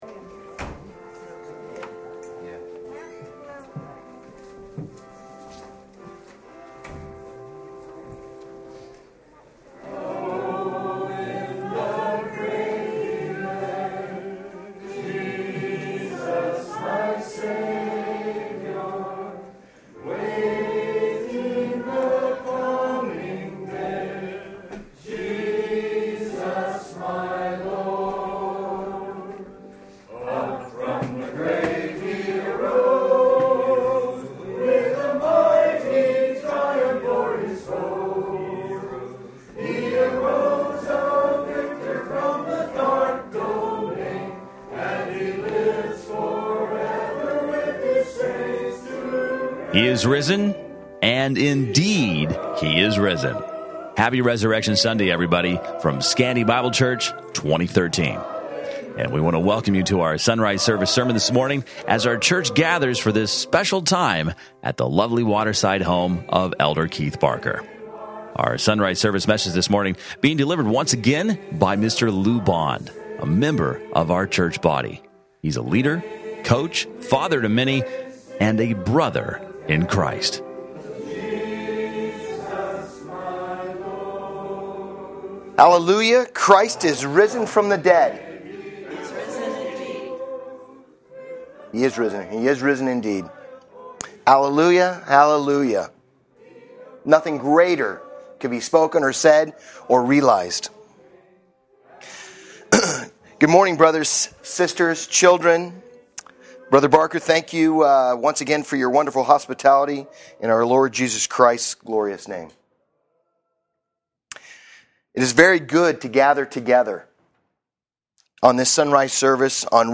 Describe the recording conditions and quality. Easter Sunrise Service